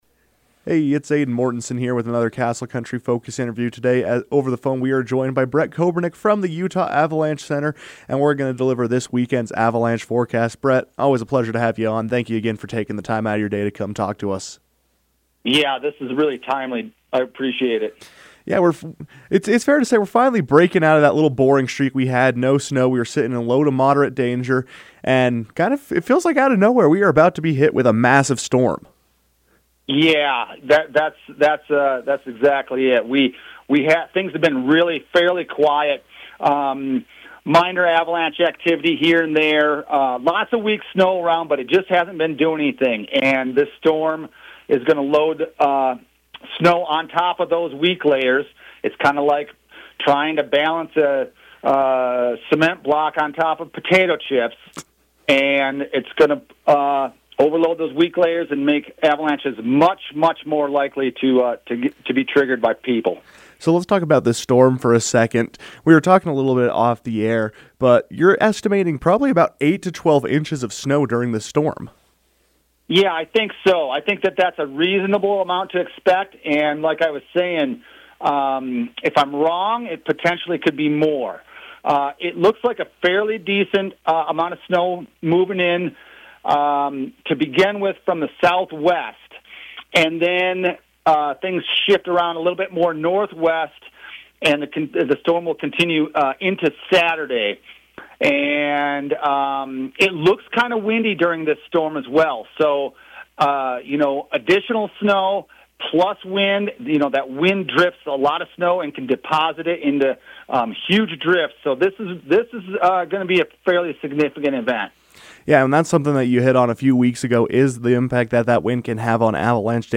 called into the KOAL newsroom to discuss these dangerous conditions and advise those wanting to explore the high country over the weekend on what to avoid.